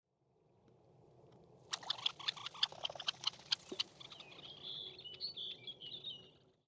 Red-Necked Phalarope